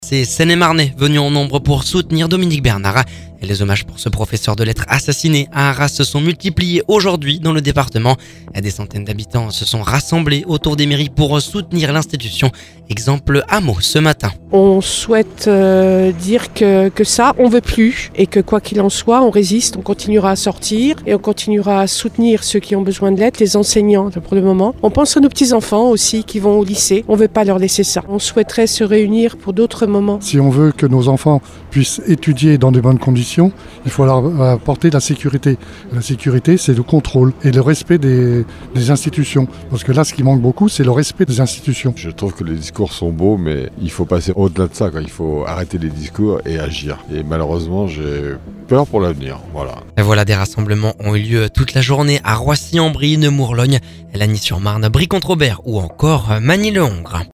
Exemple à Meaux ce matin…